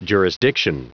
Prononciation du mot jurisdiction en anglais (fichier audio)
Prononciation du mot : jurisdiction